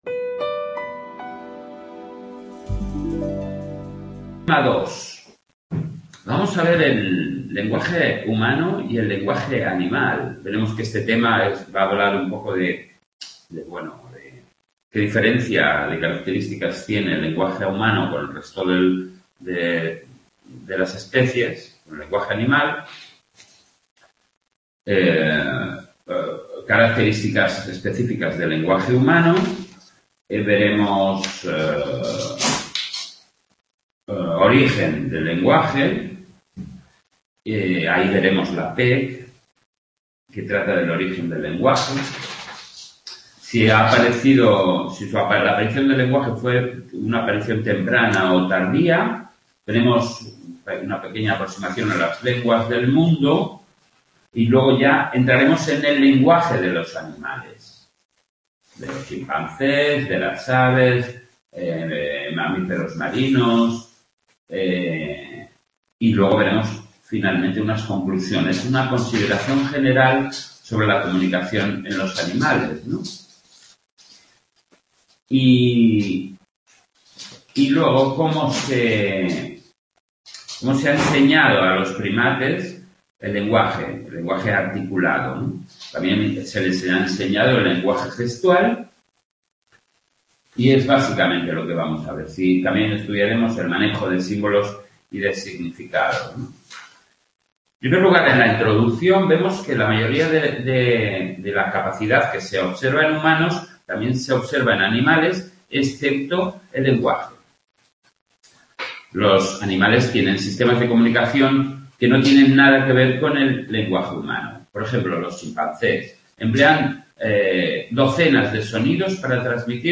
en el Centro UNED de Sant Boi